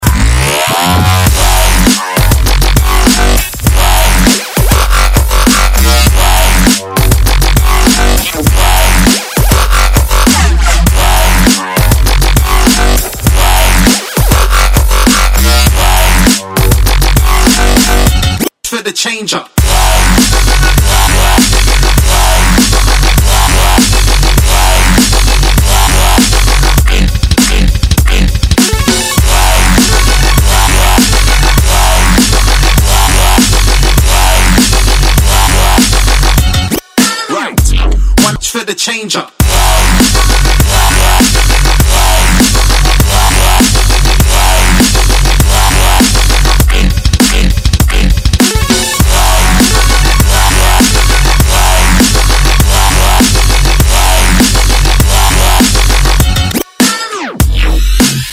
• Качество: 128, Stereo
мощный энергичный Dubstep